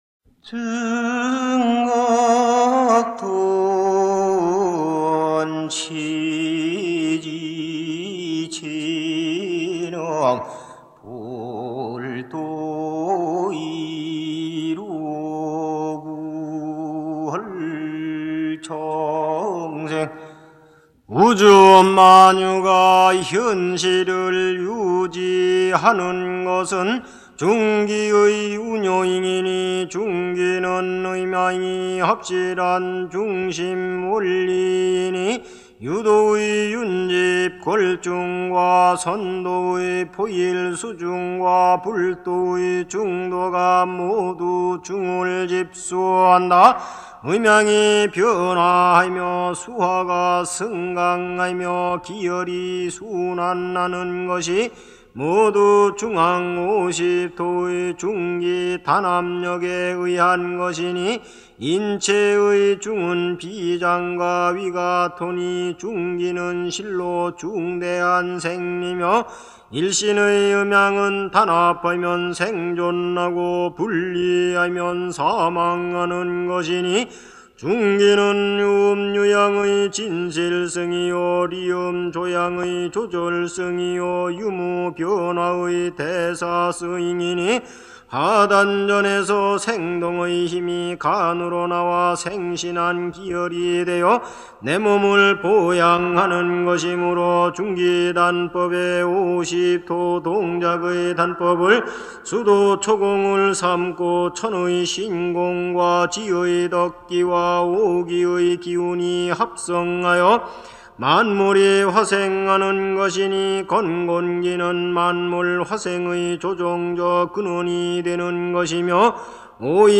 Mantra k dechové meditaci
Mantra pro dechovou meditaci podporuje soustředění a vnitřní klid. Nastavuje rytmus pro nádech a výdech a určuje, kdy se mění pozice v dechové části praxe.
sdo.chant_-1.mp3